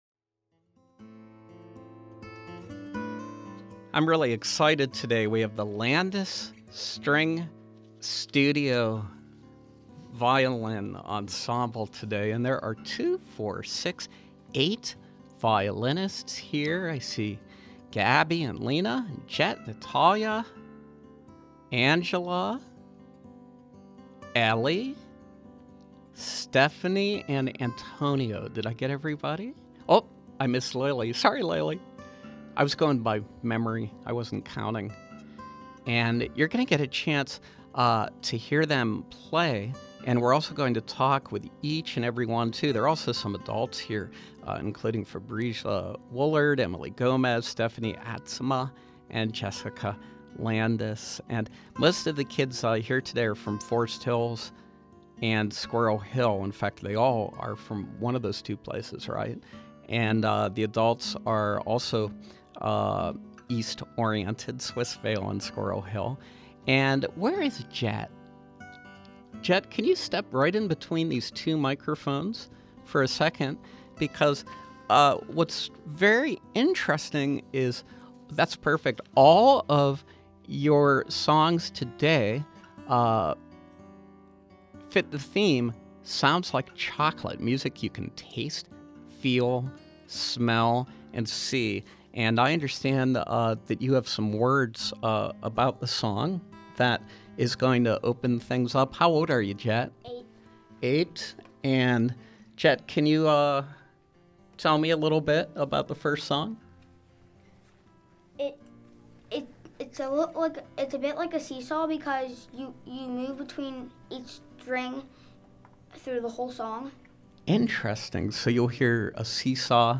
From 12/1/12: Student violinists from the Landes String Studio performing live